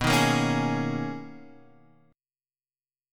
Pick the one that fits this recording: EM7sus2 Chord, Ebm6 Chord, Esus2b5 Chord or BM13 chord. BM13 chord